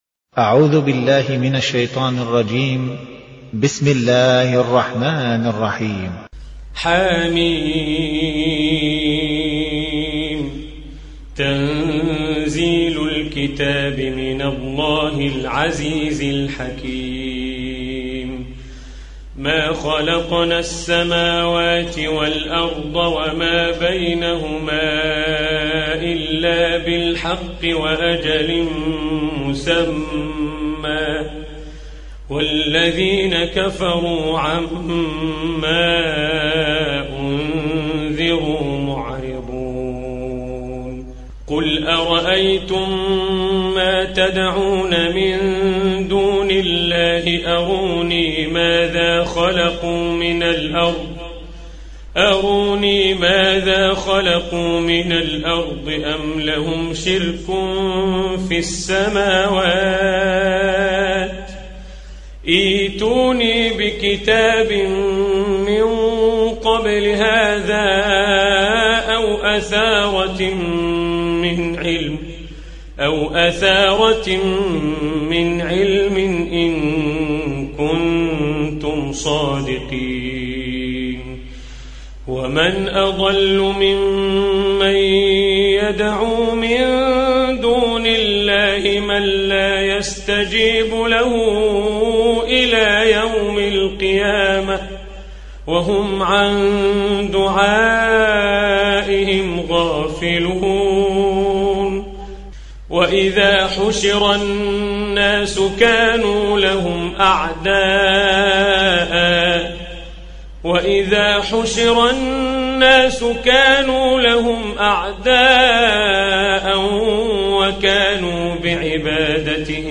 Чтение Корана